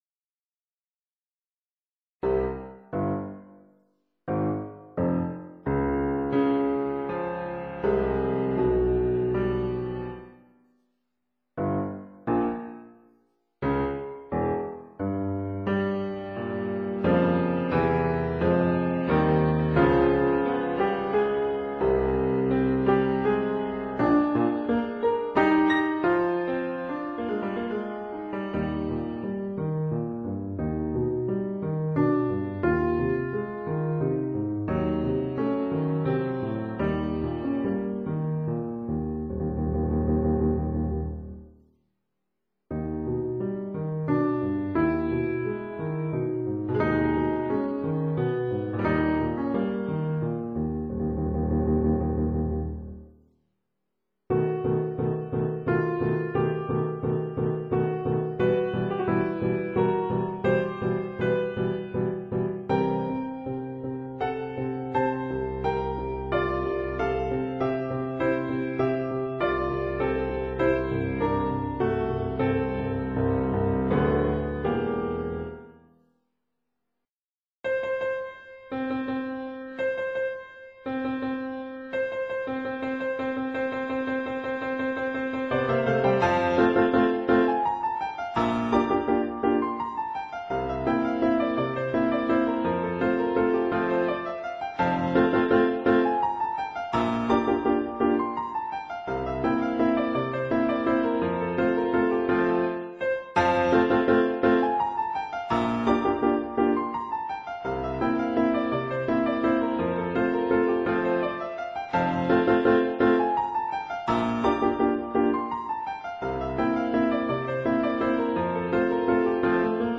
Le Tournoi, Grande valse pour piano, dédicacée “à Mme J. de Saint Projet” (Paris, Schonenberger, 28 Bd Poissonnière, S. 1269).